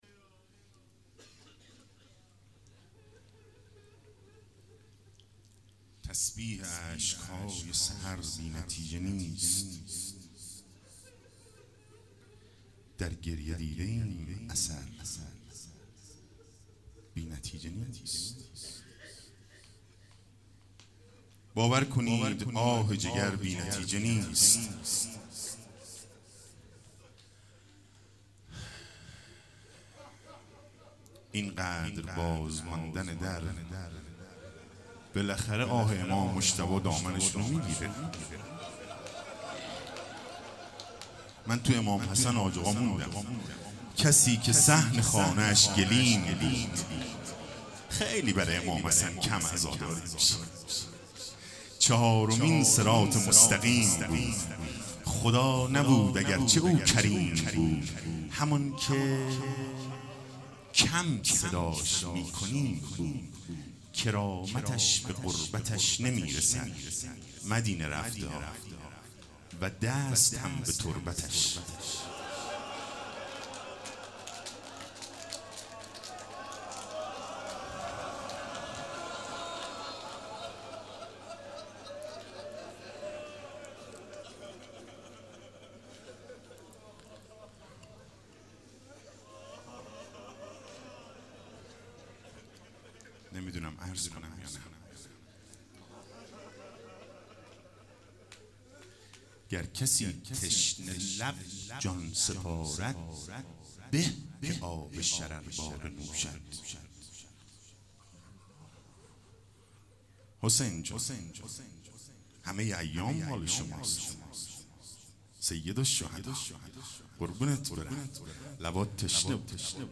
تسبیح اشک های سحر بی نتیجه نیست/روضه